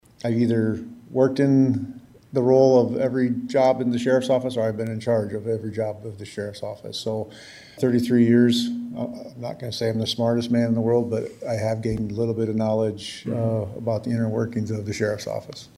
HE SPOKE TO SUPERVISORS AT THEIR TUESDAY MEETING: